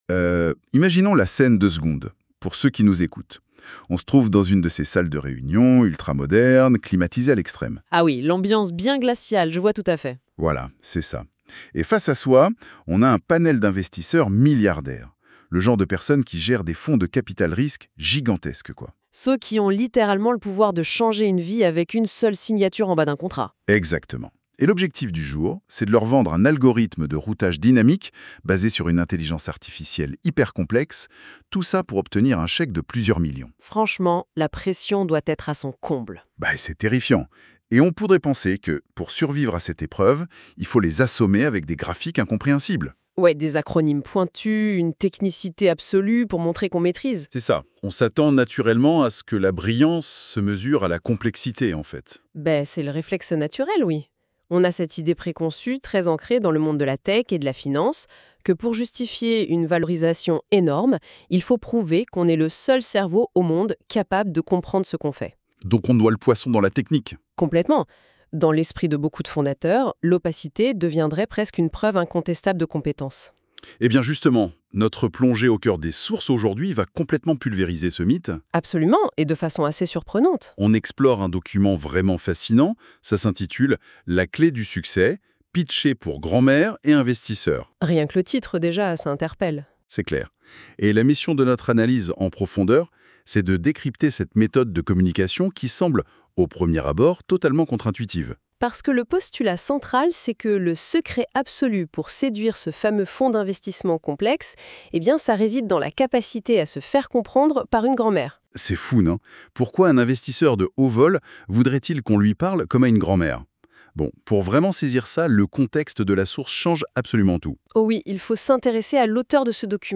Débat à écouter ici (12.43 Mo) Ce débat aborde plutôt les questions sous deux angles stratégiques pour réussir un pitch : « Avez-vous déjà pensé à comment résoudre le problème X ?